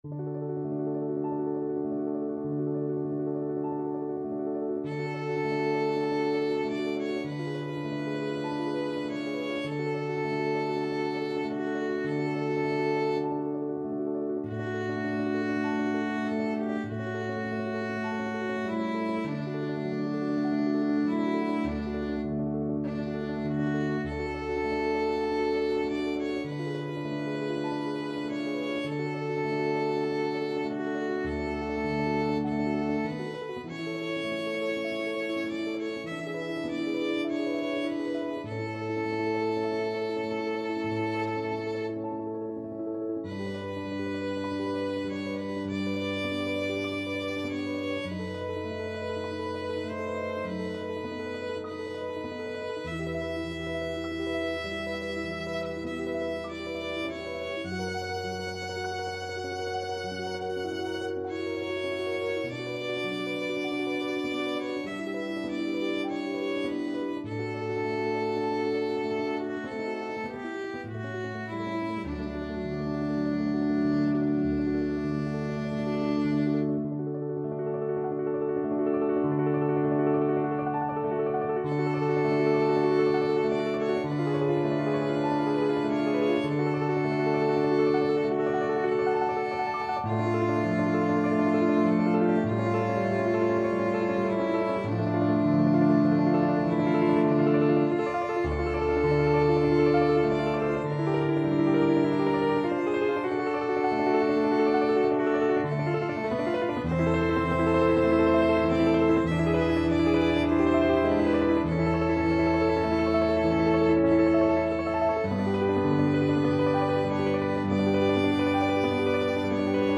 2/2 (View more 2/2 Music)
Sanft bewegt
Classical (View more Classical Violin Music)